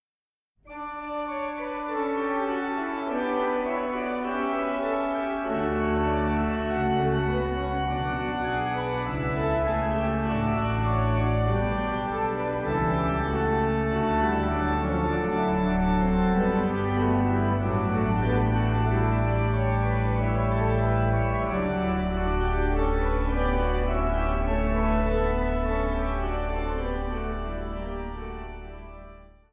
Führer-Skrabl-Orgel in der Evangelischen Kirche Saarlouis